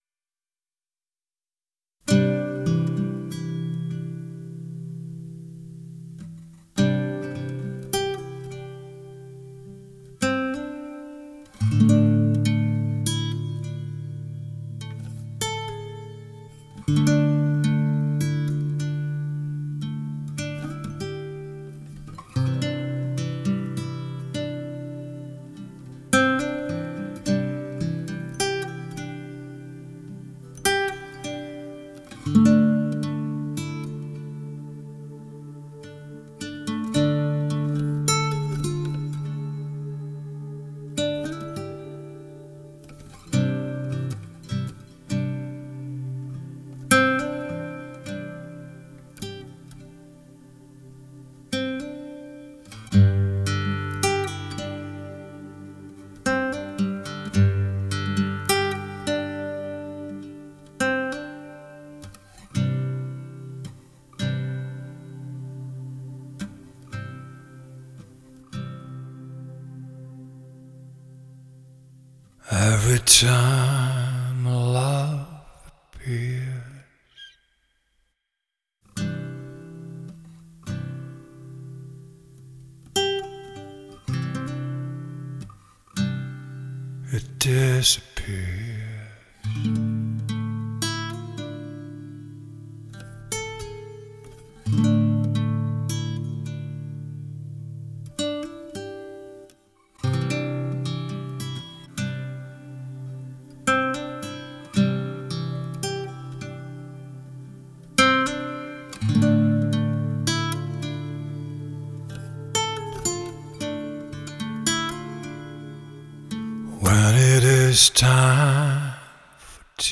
nurtu piosenki autorskiej
Czasem stosowane s� skrzypce lub keyboard